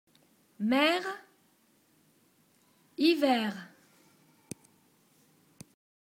• et quelques substantifs comme : mer, hiver